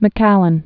(mĭ-kălən)